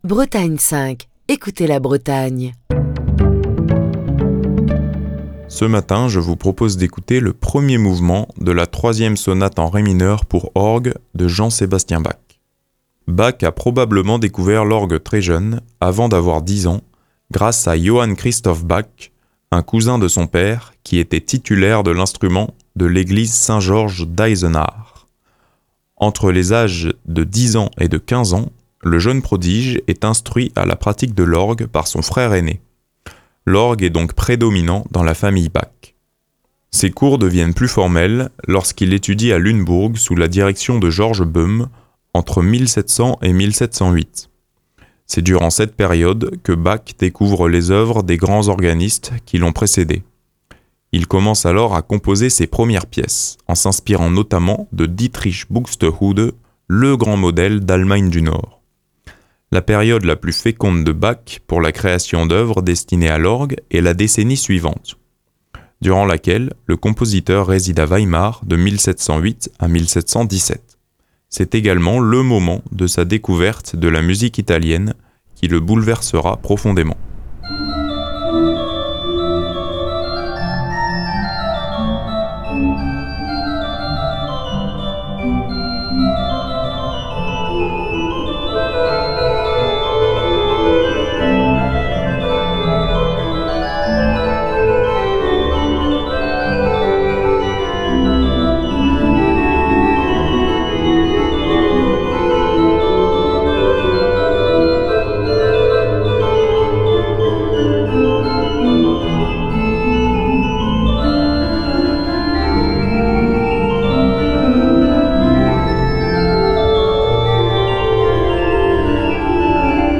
Ce matin je vous propose d’écouter un mouvement de la troisième sonate en ré mineur pour Orgue de Jean Sébastien Bach.